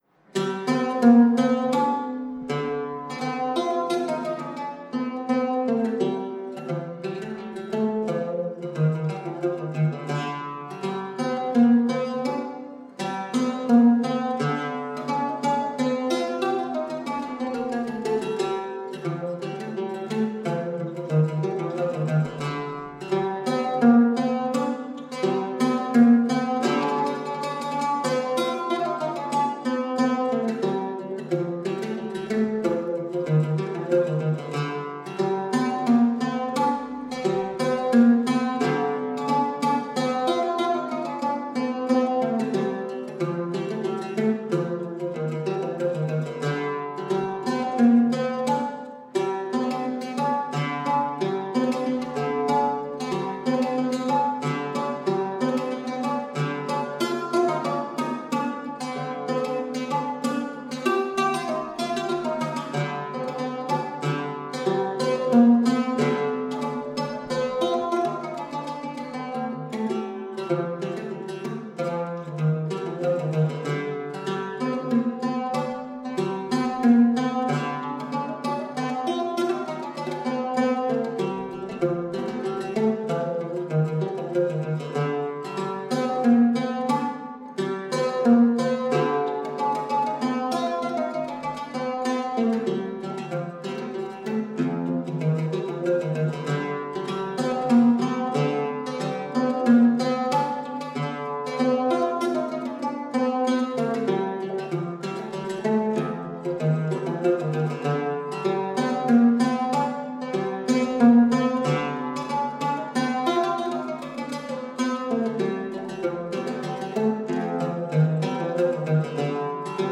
ムハイヤル旋法ムワッシャフ《マフブービー・カサド》